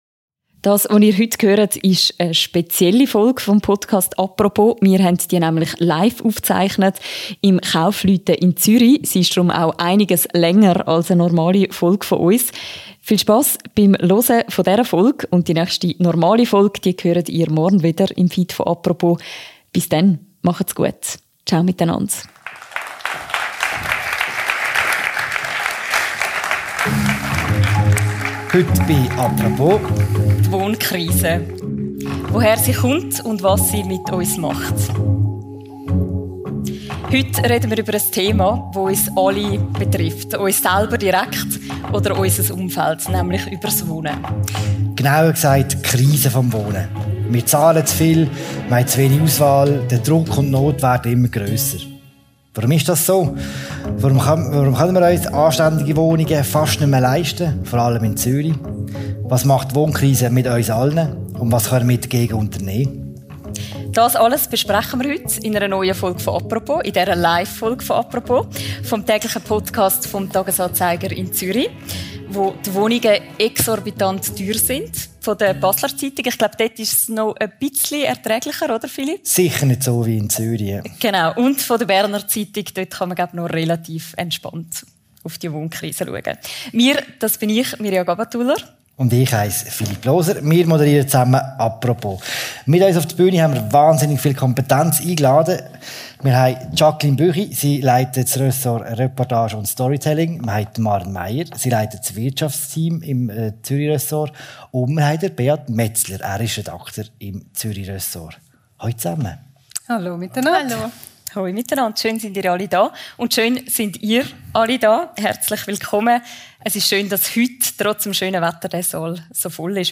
Der Wohnraum wird knapper und teurer. Bei der Live-Premiere des Podcasts «Apropos» diskutierten unsere Expertinnen und Experten darüber, was das für Folgen hat.